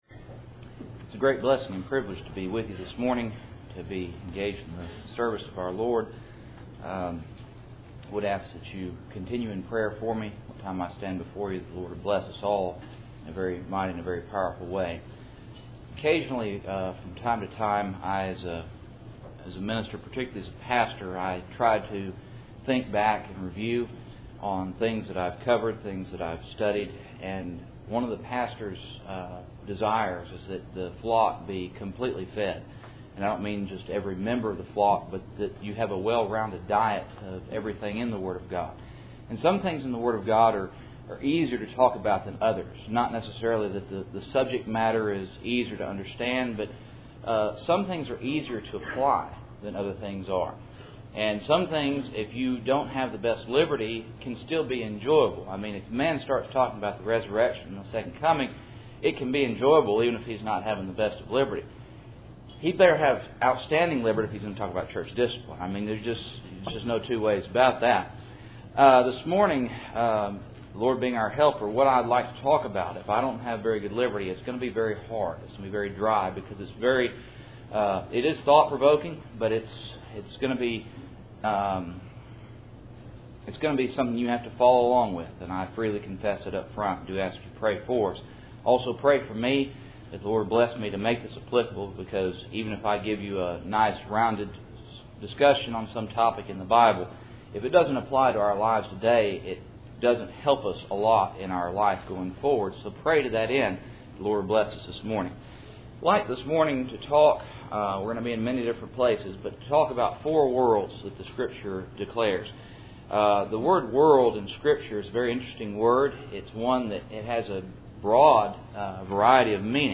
Cool Springs PBC Sunday Morning